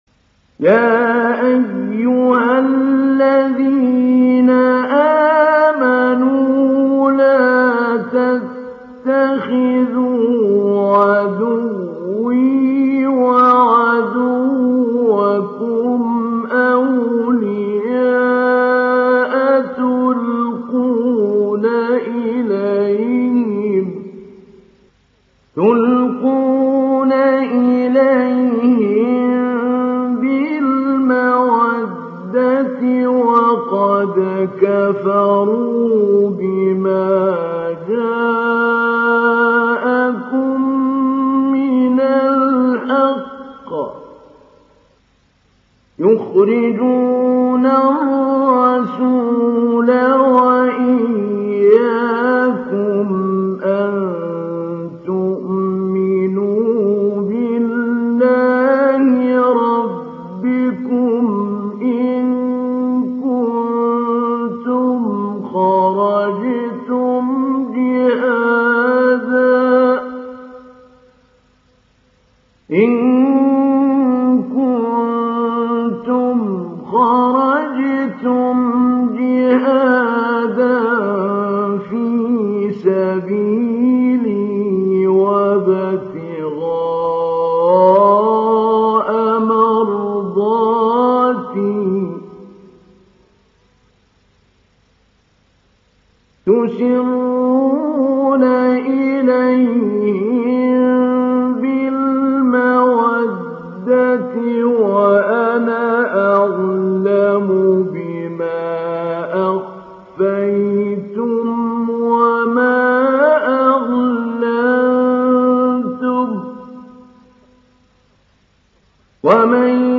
Surat Al Mumtahinah mp3 Download Mahmoud Ali Albanna Mujawwad (Riwayat Hafs)